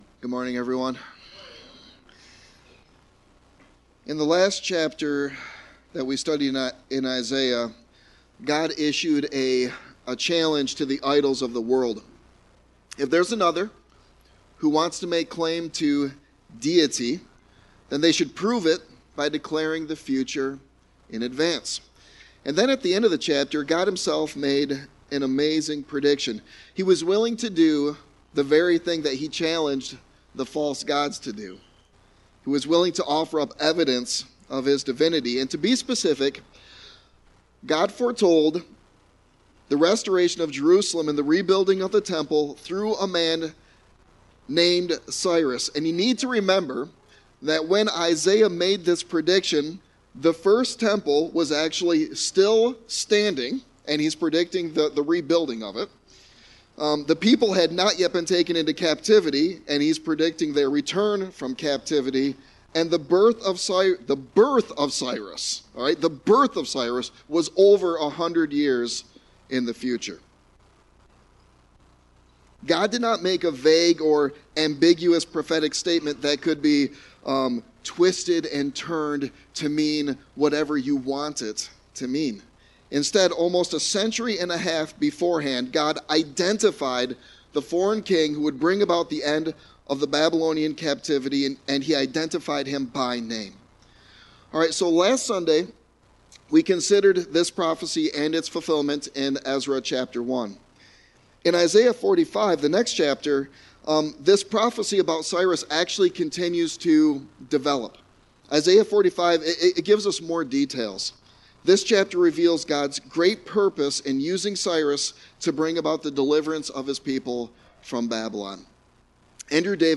Sermon Text: Isaiah 45:1-25